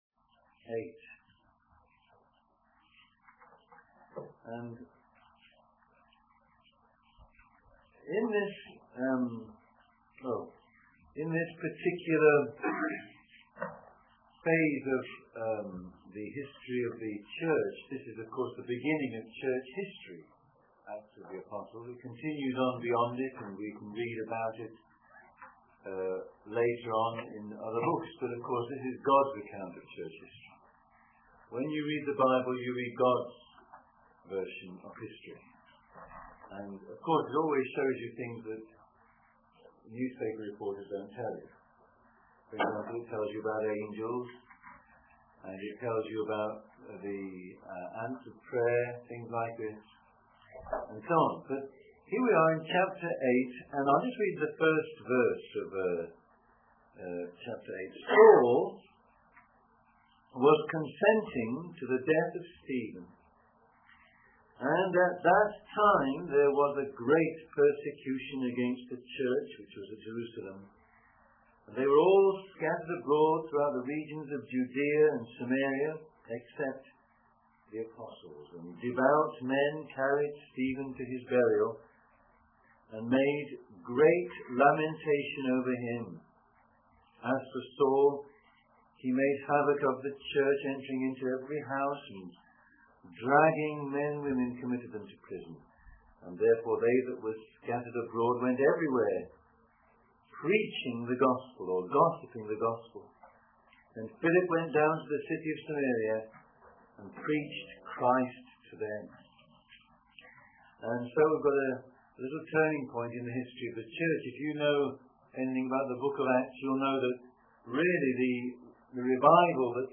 In this sermon, the preacher emphasizes the importance of giving away one's possessions and living a life free from sin.